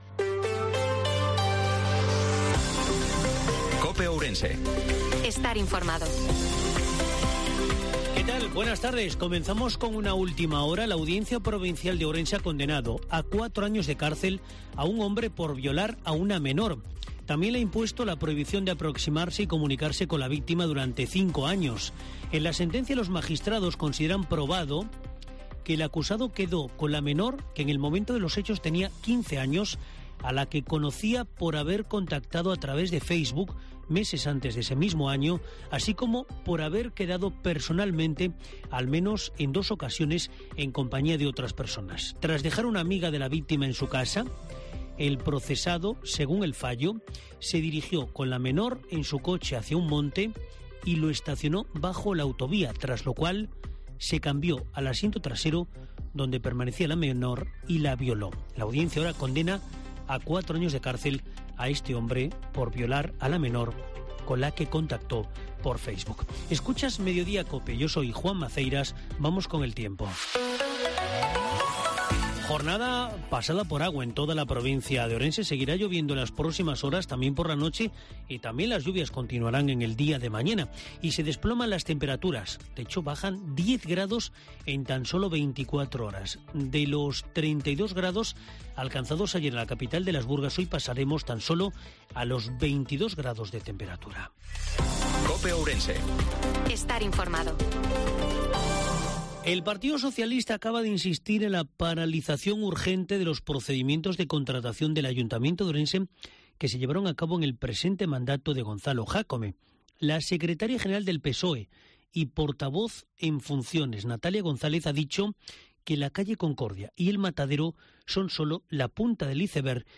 INFORMATIVO MEDIODIA COPE OURENSE-07/06/2023